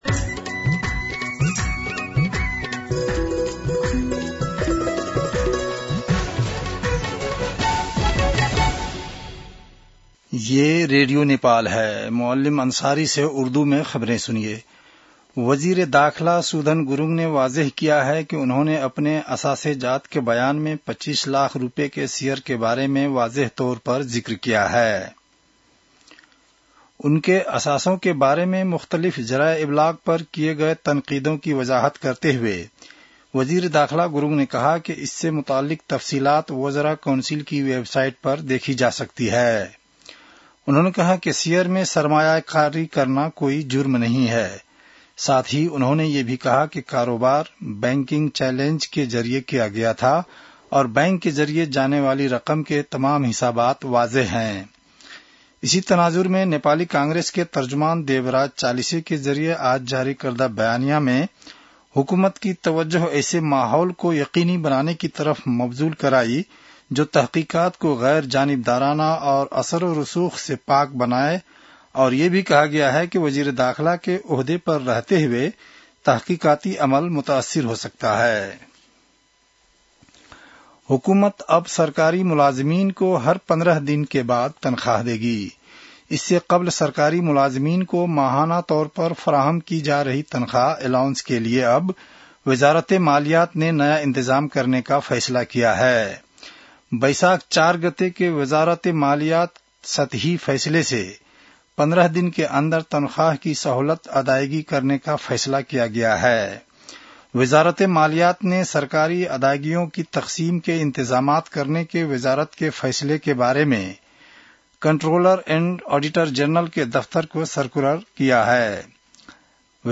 उर्दु भाषामा समाचार : ७ वैशाख , २०८३